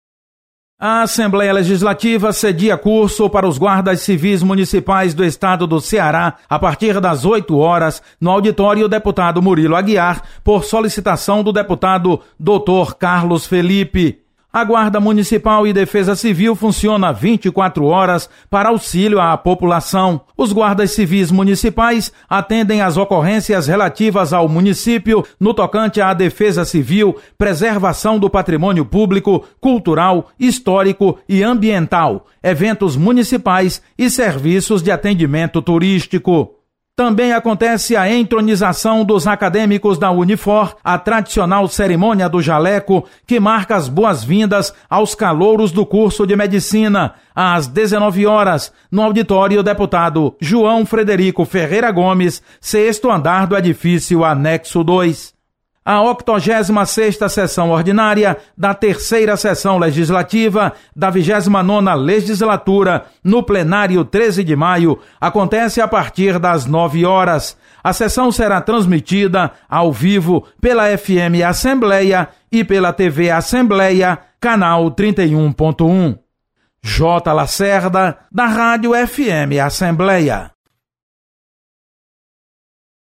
Acompanhe as informações das atividades da Assembleia legislativa nesta terça-feira. Repórter